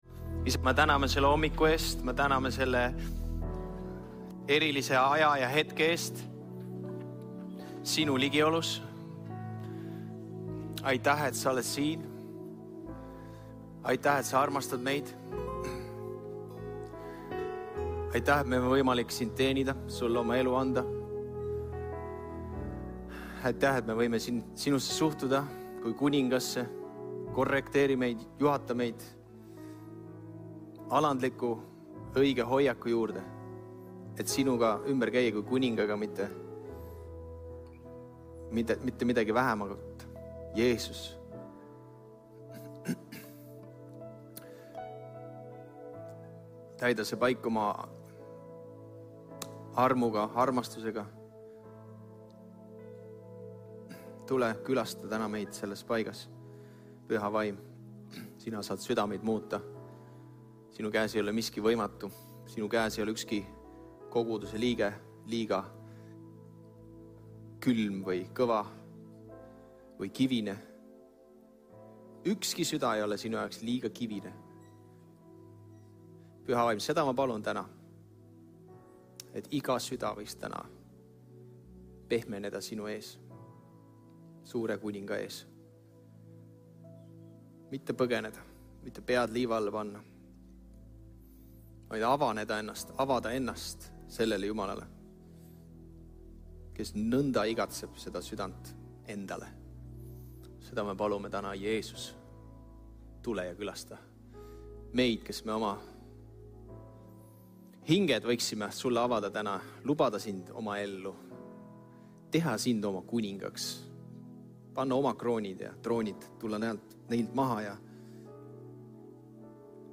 Jutlused - EKNK Toompea kogudus
Kristlik ja kaasaegne kogudus Tallinna kesklinnas.